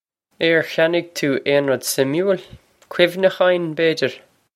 Pronunciation for how to say
Air khyan-ig too ayn rud sim-ool? Khwiv-nukh-aw-in, bay-dyir?
This is an approximate phonetic pronunciation of the phrase.